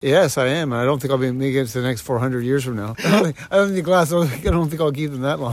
This man told myFM it was incredible to share it with others.